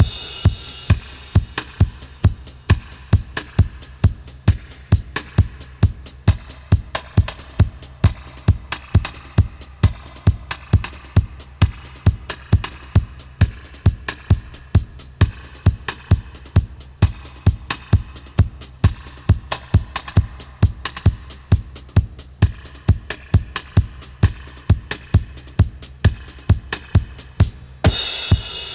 134-DUB-02.wav